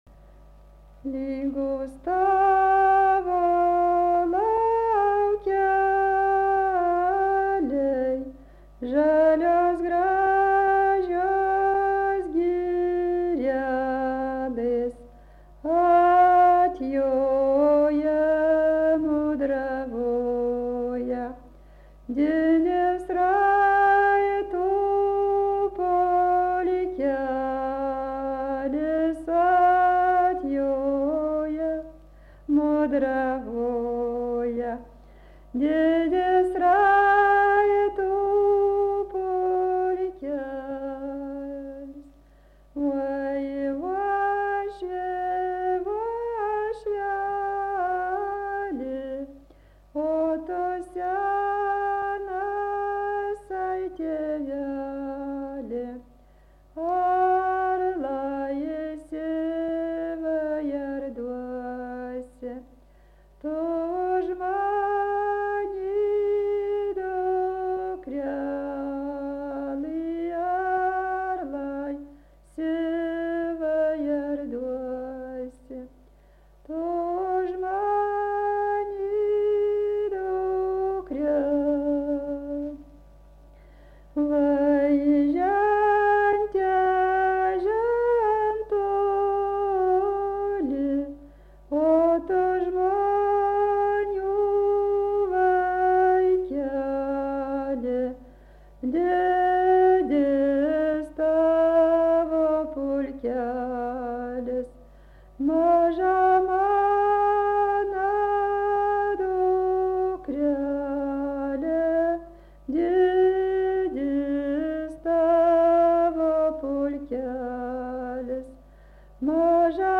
Dalykas, tema daina
Erdvinė aprėptis Dargužiai
Atlikimo pubūdis vokalinis